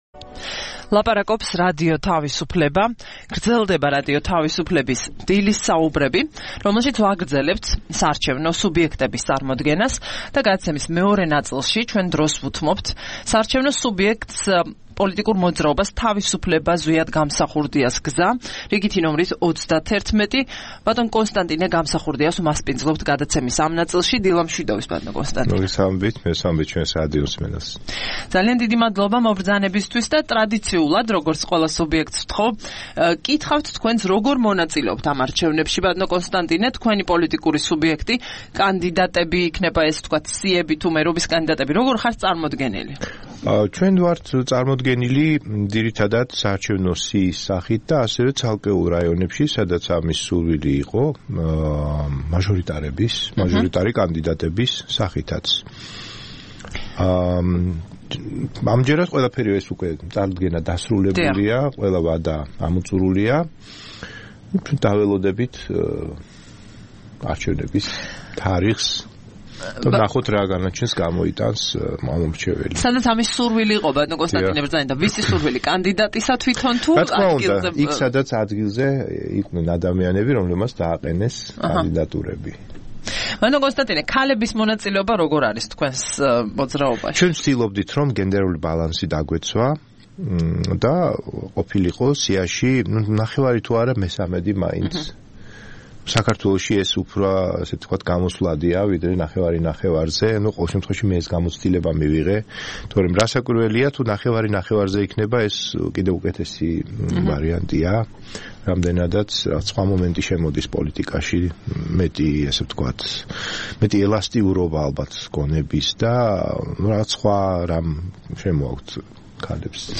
სტუმრად ჩვენს ეთერში: კონსტანტინე გამსახურდია